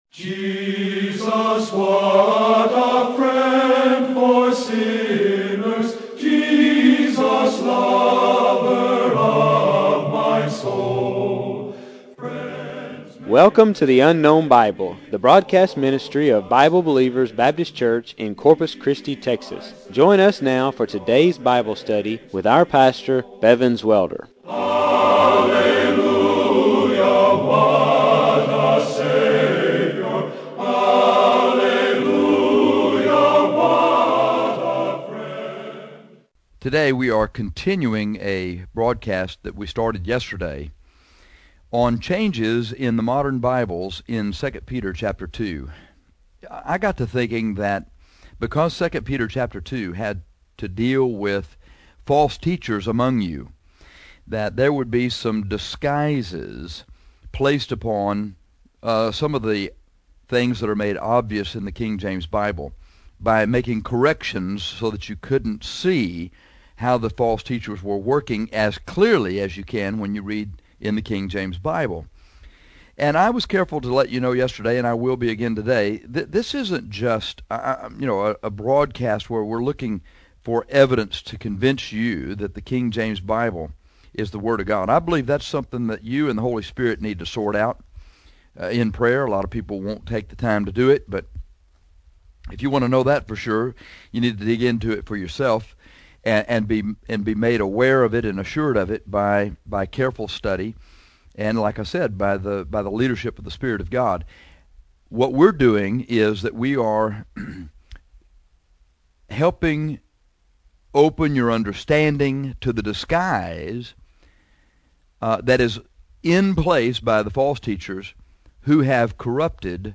This lesson is Part 2 about changes in 2 Peter 2.